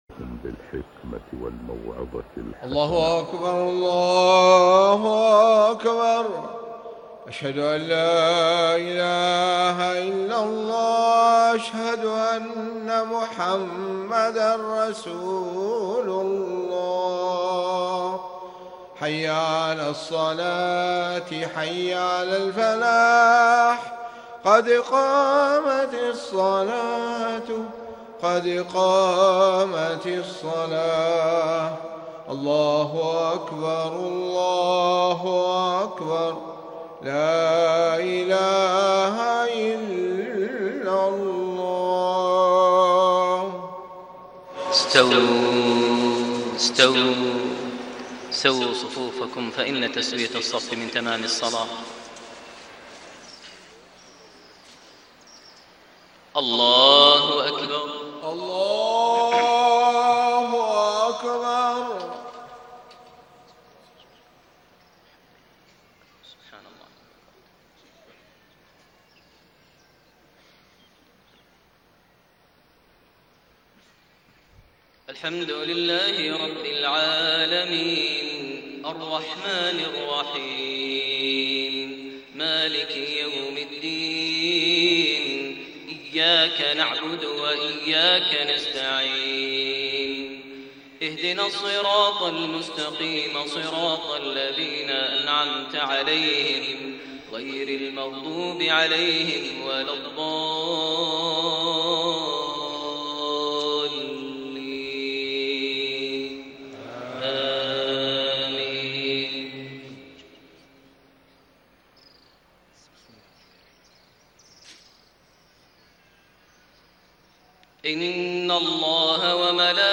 صلاة المغرب7-3-1432 من سورة الأحزاب 56-68 > 1432 هـ > الفروض - تلاوات ماهر المعيقلي